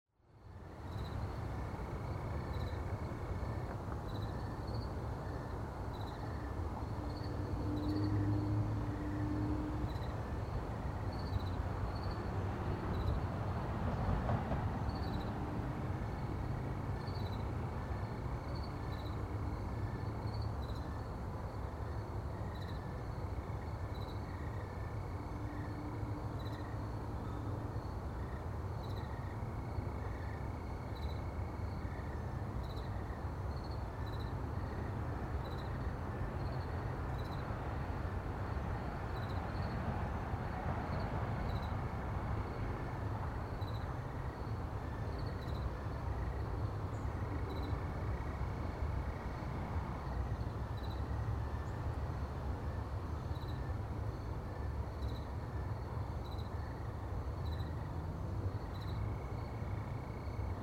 Tiếng thiên nhiên
Âm thanh tiếng Côn trùng vào Ban đêm